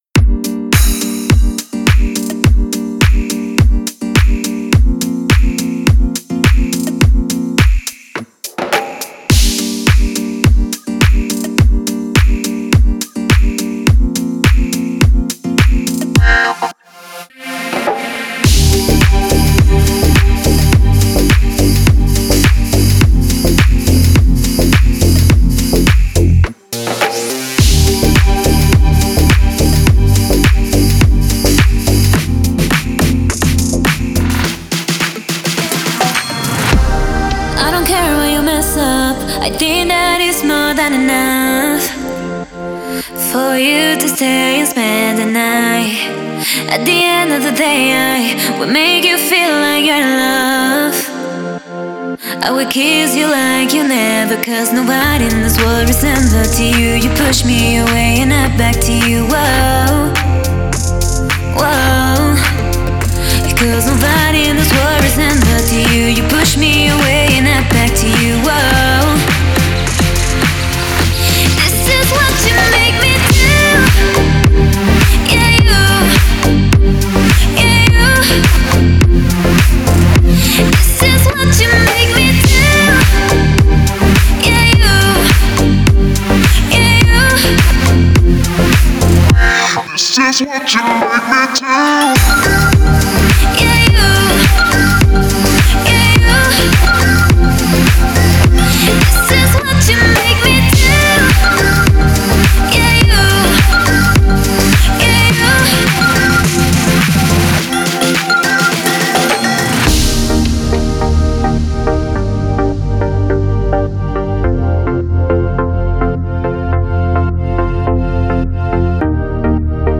Стиль: Deep House / Dance / Pop